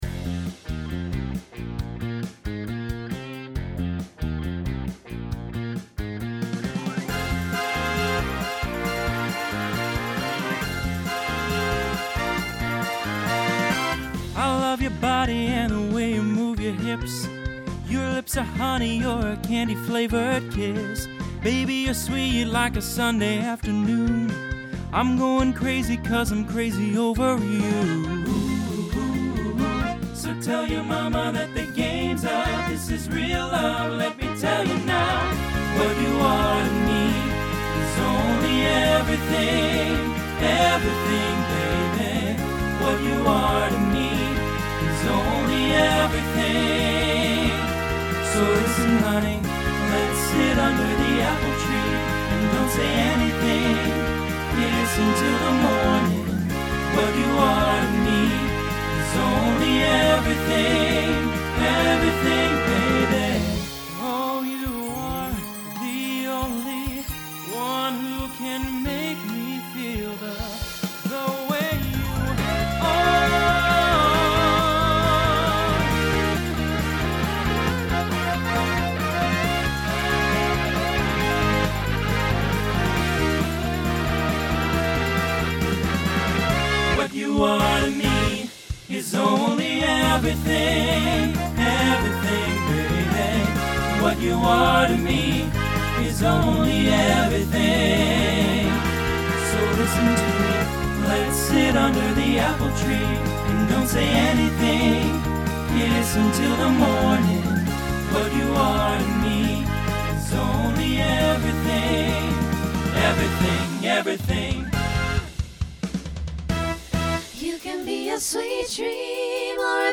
Starts TTB, then SSA, then SATB.
2010s Genre Pop/Dance , Rock
Transition Voicing Mixed